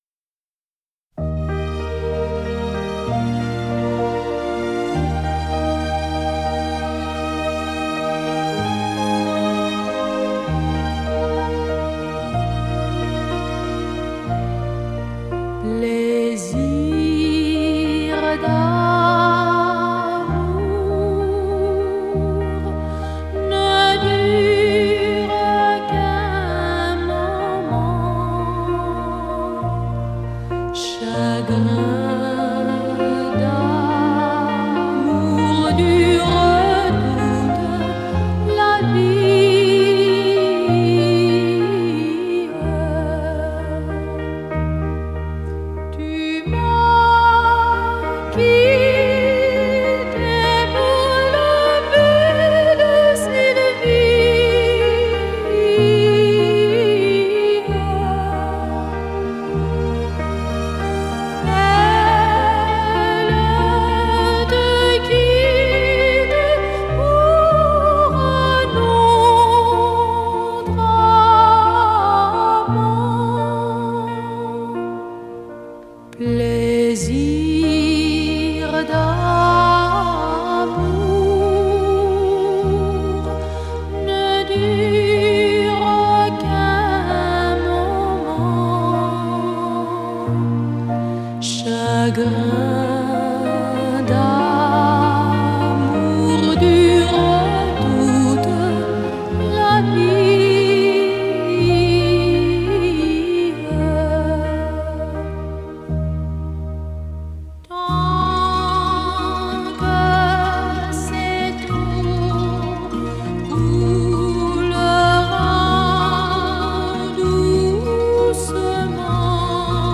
メロディはたいへん甘美で、タイトルもロマンティックだが、歌詞は愛する女性に裏切られた男性の、女々しい失恋歌となっている。